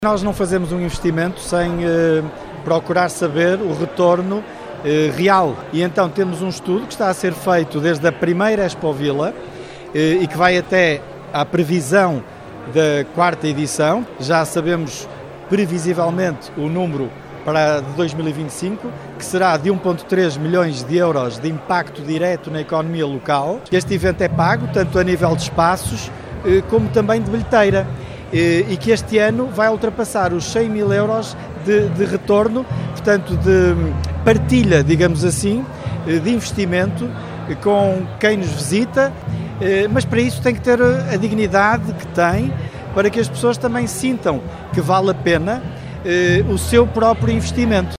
O autarca Pedro Lima adianta que se espera um retorno financeiro de 1 milhão e 300 mil euros para a economia local.